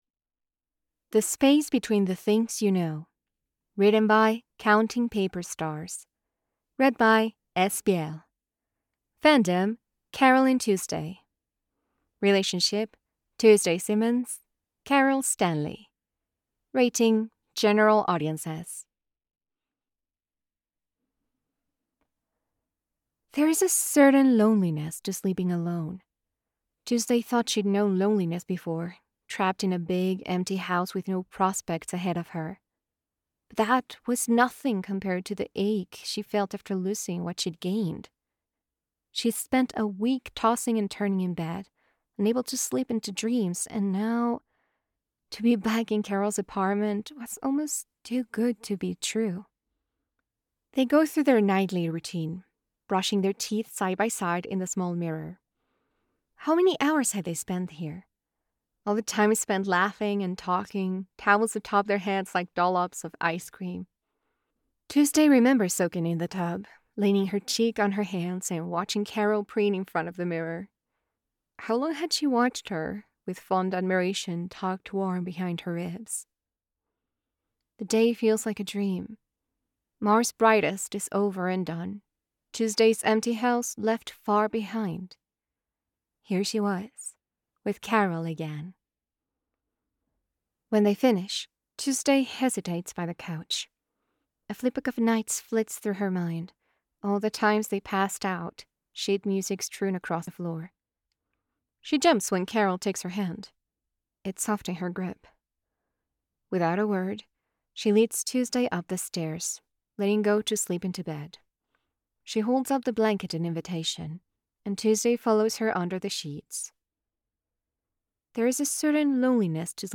no music: download mp3: here (r-click or press, and 'save link') [6 MB, 00:07:51]